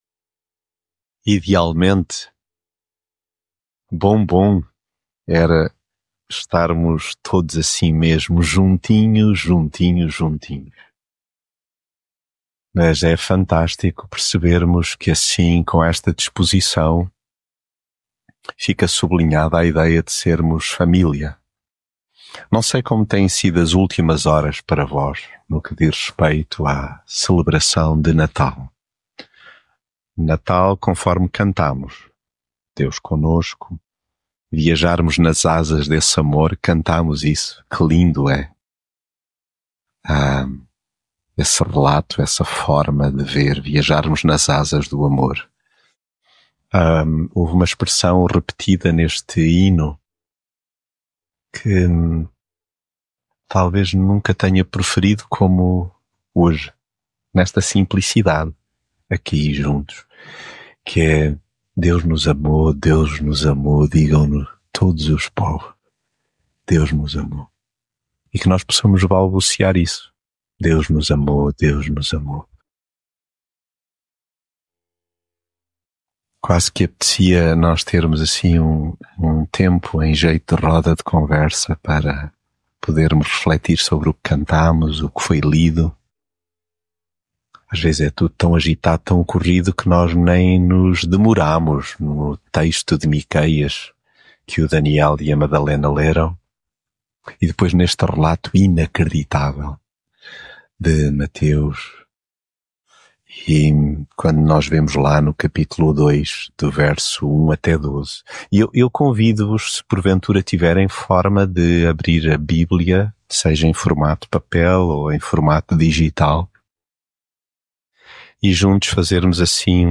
mensagem bíblica Há viagens longuíssimas que custam imenso a fazer, de tal modo que ao fim de umas escassas dezenas de quilómetros já ecoa a...